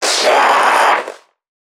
NPC_Creatures_Vocalisations_Infected [37].wav